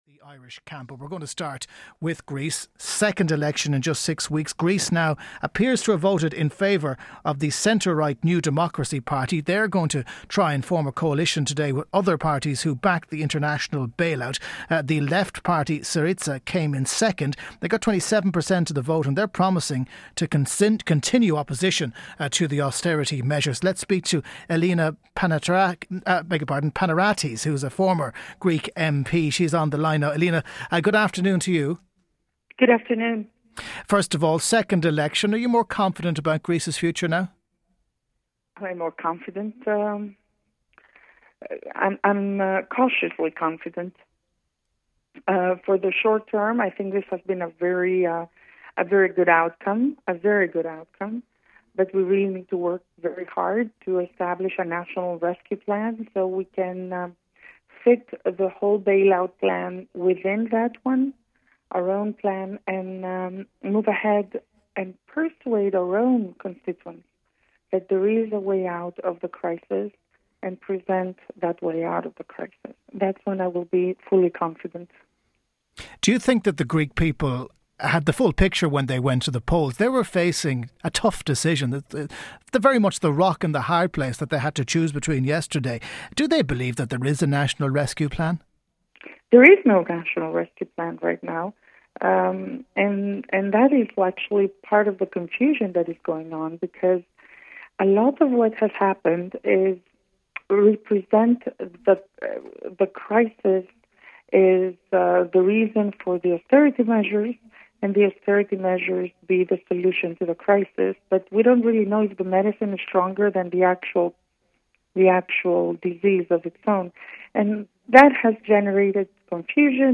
for “Lunchtime” on Newstalk 106-108fm (Ireland’s National Independent Talk Radio Broadcaster)